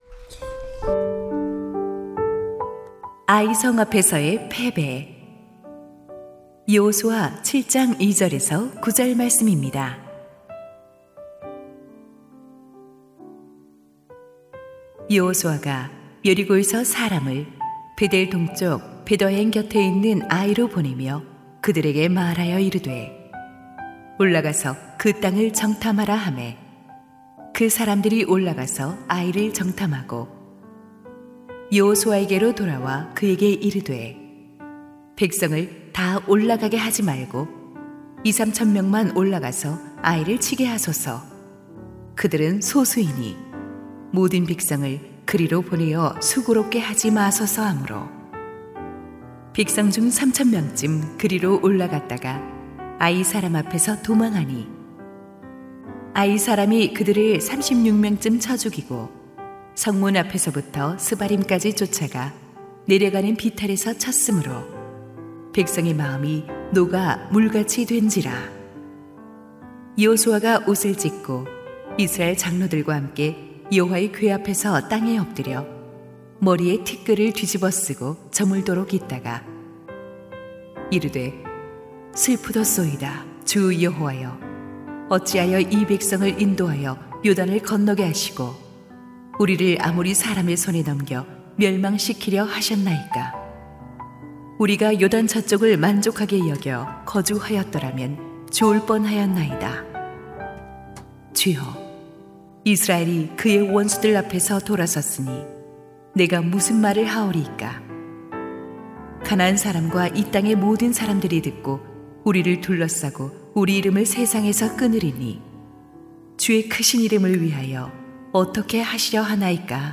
2025-09-11 약속의 땅을 위한 특별새벽기도회
> 설교